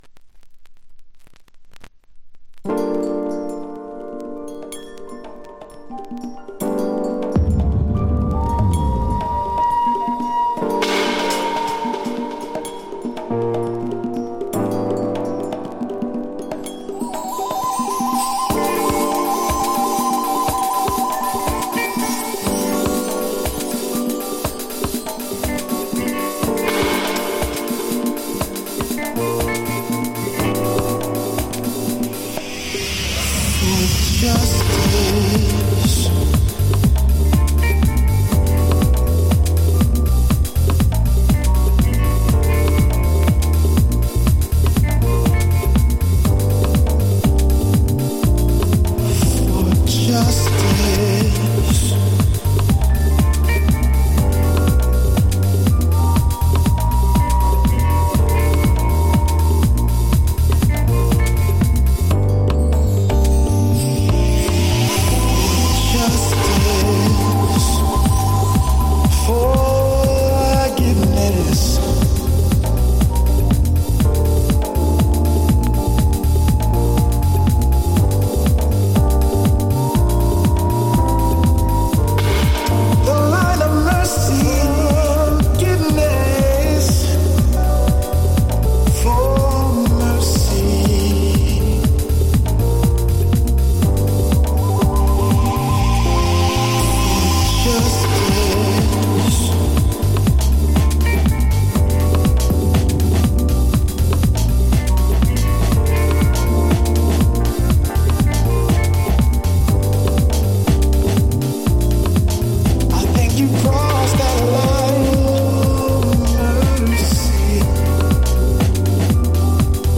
甘い！そしてかっこいい！